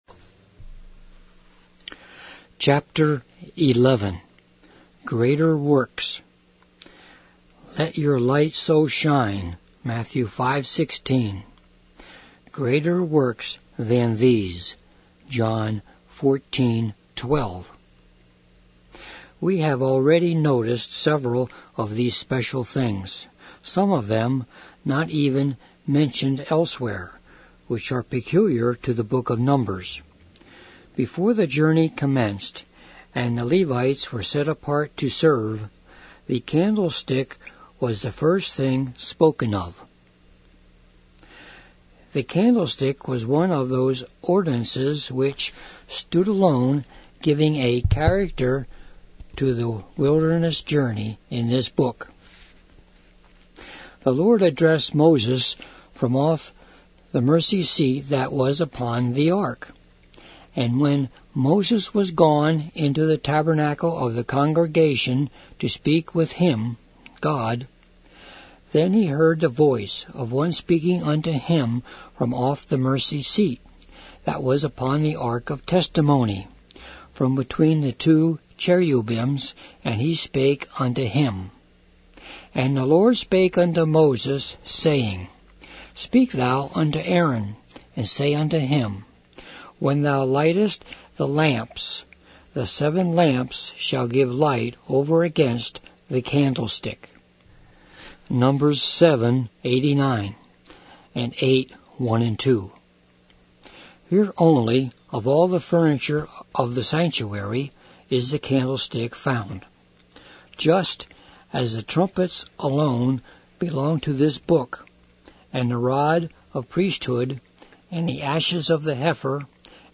Bible Ministry Recordings MP3 Audio Book